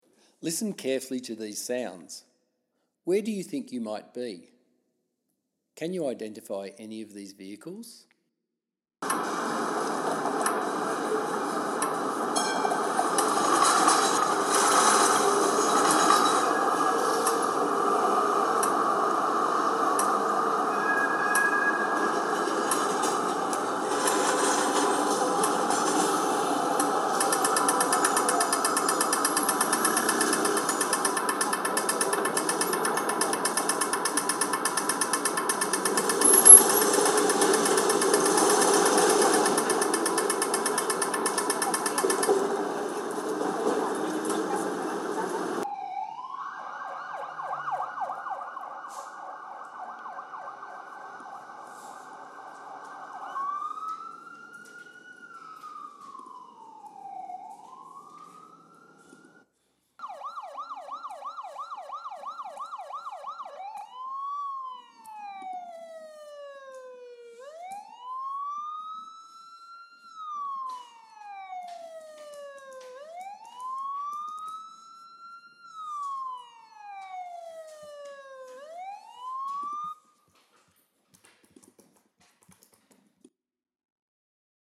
Resource-4.1_Traffic-sounds.mp3